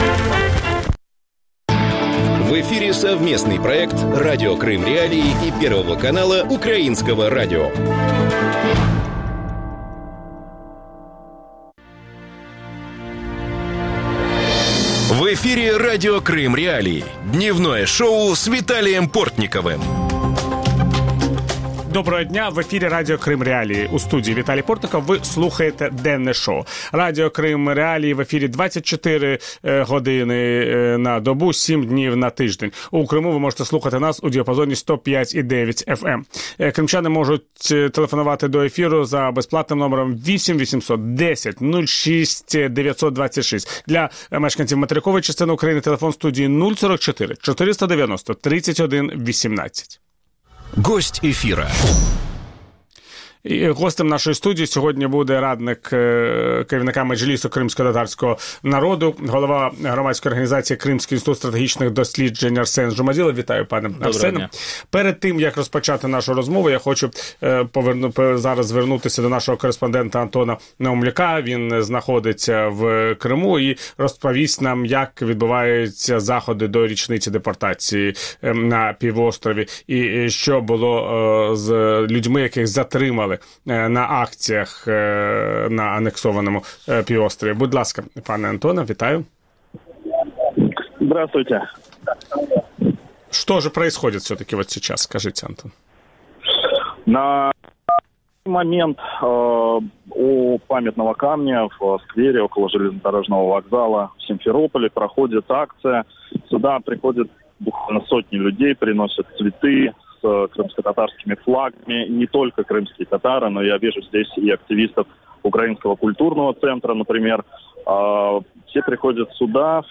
Об этом – в проекте «Дневное шоу» в эфире Радио Крым.Реалии с 12:10 до 12:40.
Ведущий – Виталий Портников.